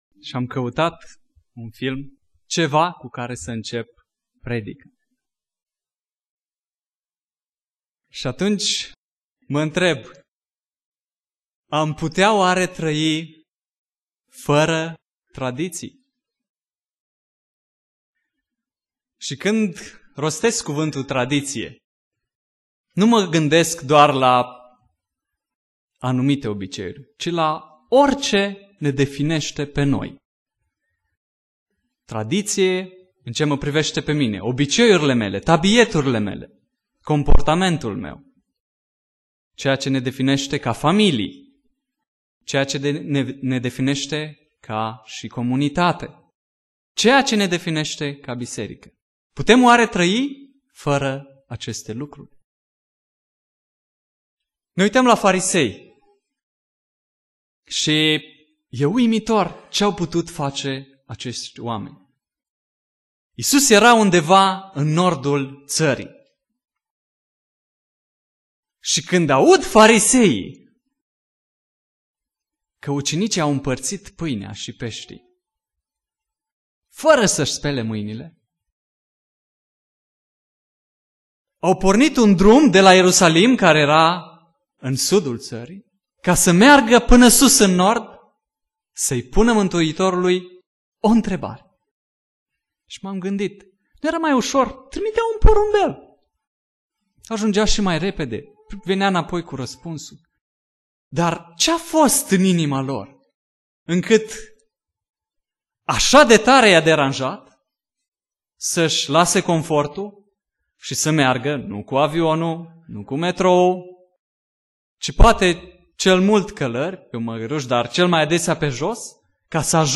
Predica Aplicatie - Matei 15